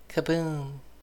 snd_bunny_pop.ogg